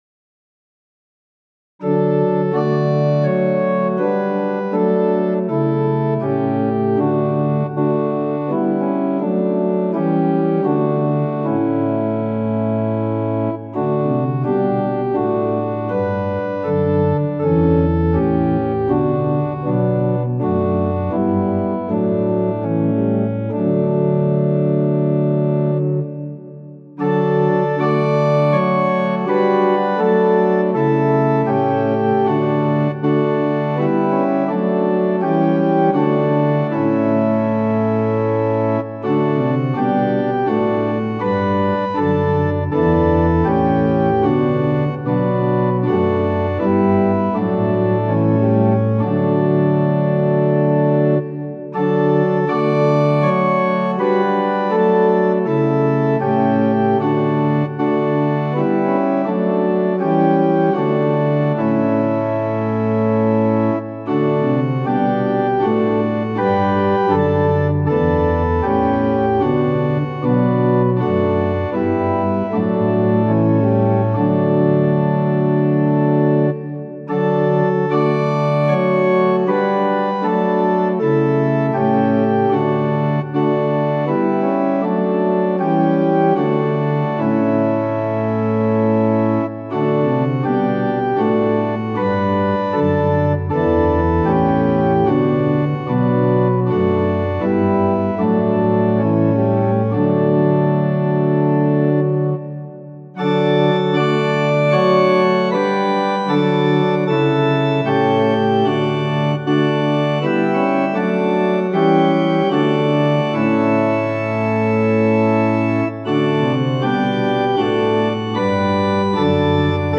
Tonality = D Pitch = 440 Temperament = Equal